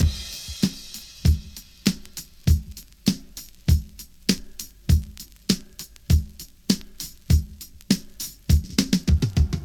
• 100 Bpm High Quality Drum Groove D# Key.wav
Free breakbeat - kick tuned to the D# note. Loudest frequency: 1499Hz
100-bpm-high-quality-drum-groove-d-sharp-key-yZc.wav